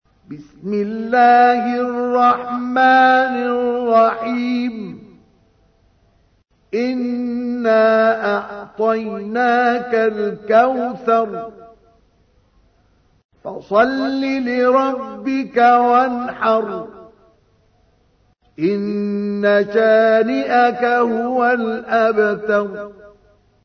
Audio icon ترتیل سوره کوثر با صدای مصطفى اسماعيل ازمصر (94.13 KB)